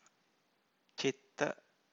Ciththa